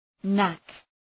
Προφορά
{næk}